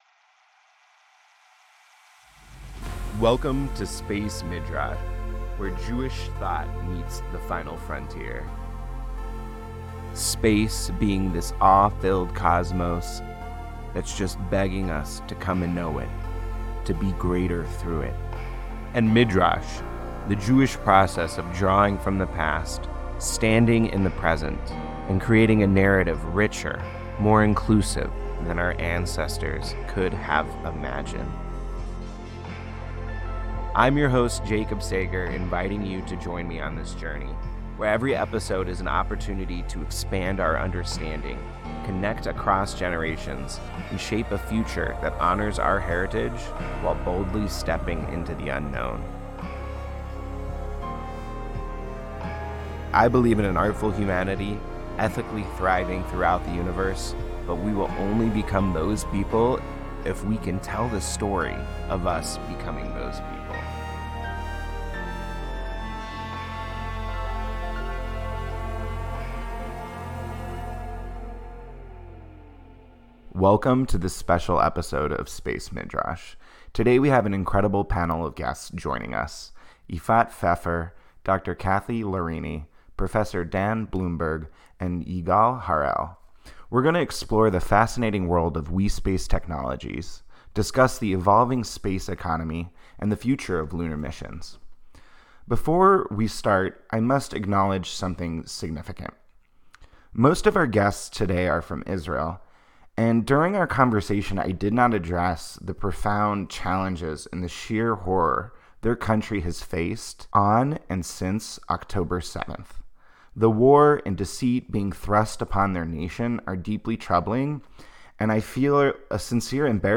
This engaging discussion delves into the innovative world of WeSpace Technologies and their pioneering lunar hoppers, the evolving landscape of the space economy, and the intersection of art and space exploration. Discover the insights on the future of lunar missions, the potential of cislunar economies, and the philosophical and ethical implications of space exploration.